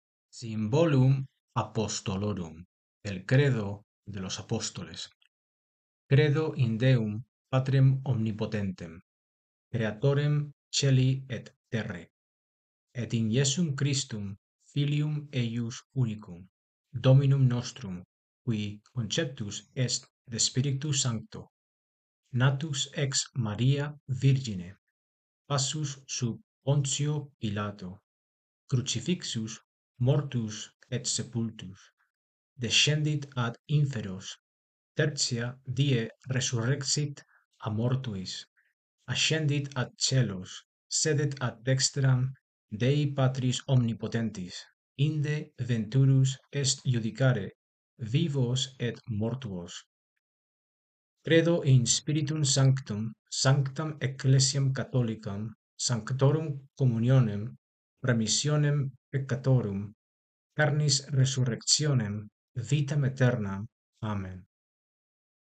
(Descarga el audio de cómo pronunciar el Symbolum Apostolorum)